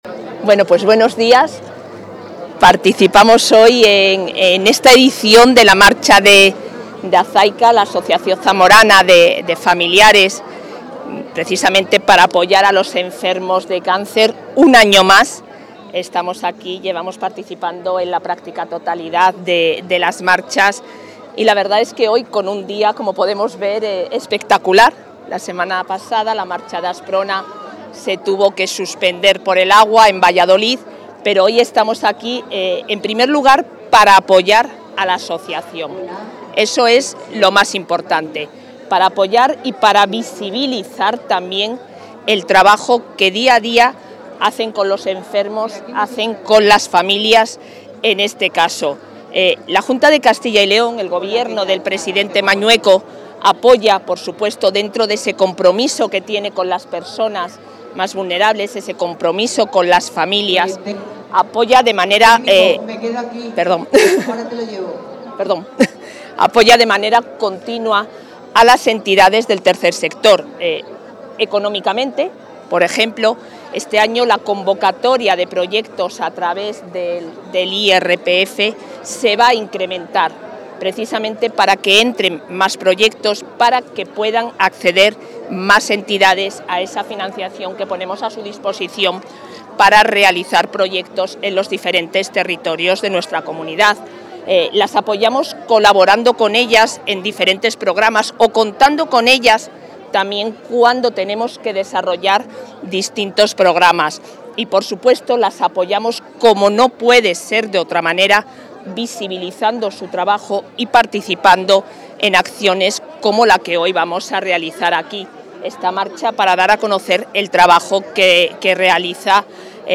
Declaraciones de la vicepresidenta.
La vicepresidenta de la Junta de Castilla y León y consejera de Familia e Igualdad de Oportunidades, Isabel Blanco, ha participado hoy en la VI Marcha Solidaria organizada por la Asociación Zamorana de Ayuda frente al Cáncer (Azayca), que ha tenido lugar en Zamora.